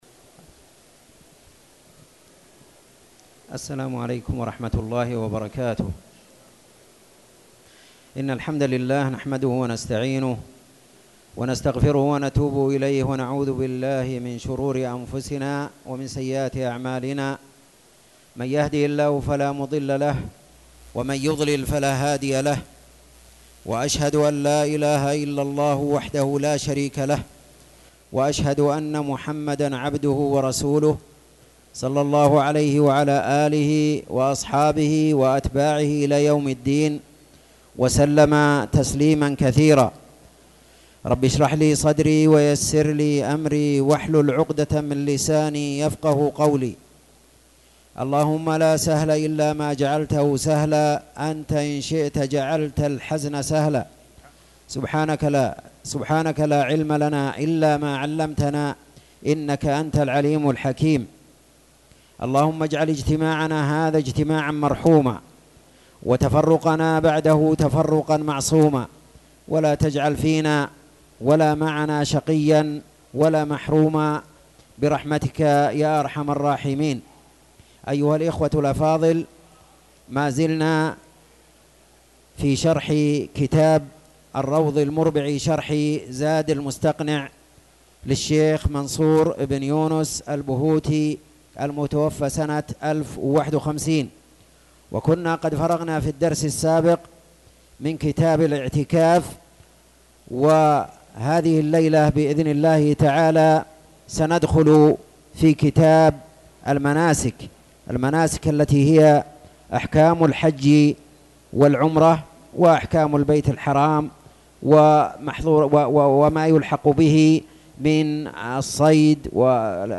تاريخ النشر ٣٠ جمادى الأولى ١٤٣٨ هـ المكان: المسجد الحرام الشيخ